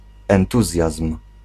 Ääntäminen
Ääntäminen Tuntematon aksentti: IPA: /ɛnˈtuzjazm/ Haettu sana löytyi näillä lähdekielillä: puola Käännös Ääninäyte 1.